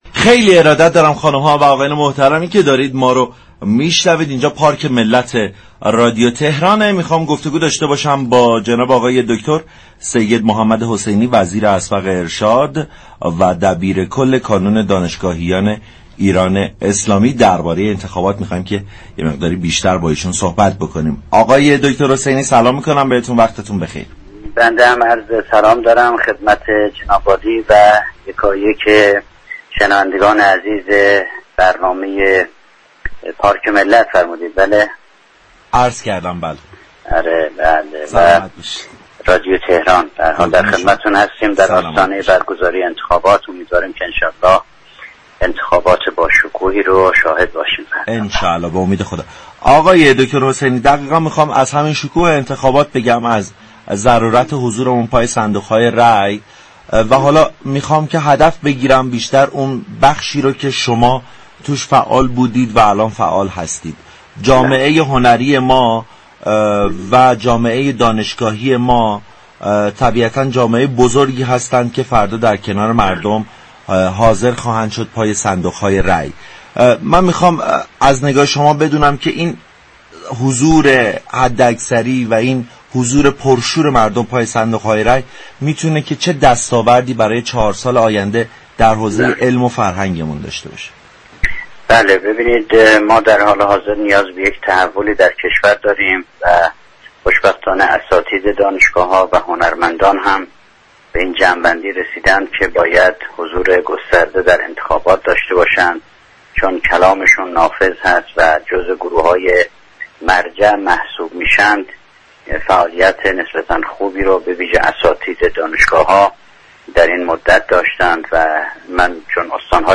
به گزارش پایگاه اطلاع رسانی رادیو تهران، سیدمحمد حسینی وزیر پیشین ارشاد و دبیر كل كانون دانشگاهیان ایران اسلامی در گفتگو با ویژه برنامه انتخاباتی پارك ملت رادیو تهران با اظهار امیدواری از برگزاری انتخابات باشكوه درباره انتخابات ریاست جمهوری و دستاورد رای جامعه دانشجویی گفت: در حال حاضر نیازمند تحول در كشور هستیم .خوشبختانه اساتید و دانشجویان و هنرمندان هم به این نتیجه رسیده‌اند كه باید فعالیت و حضور خوبی برای برگزاری هرچه بهتر انتخابات داشته باشند.تشكیل ستاد توسط بسیاری از این فرهیختگان نقطه قوت حضور آنهاست.